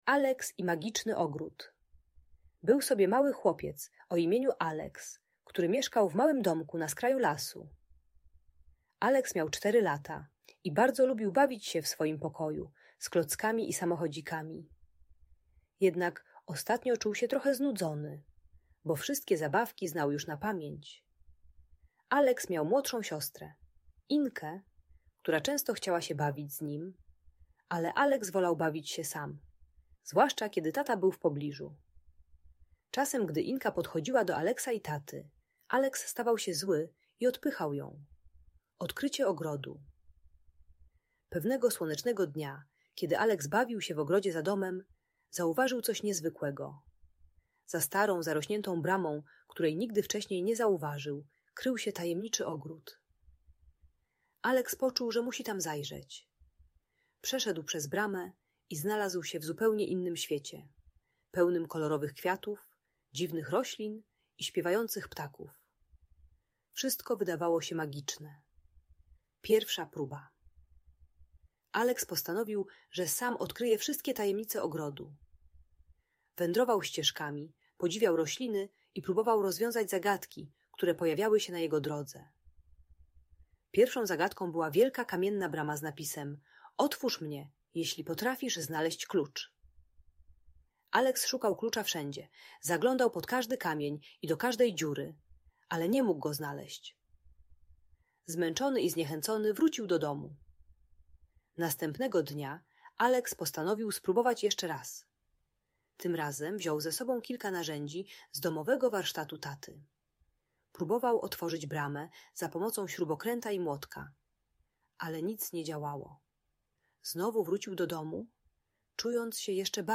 Aleks i magiczny ogród - opowieść o przygodzie i współpracy - Audiobajka